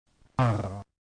[ ʀ ]
U0280 Velar or uvular tap.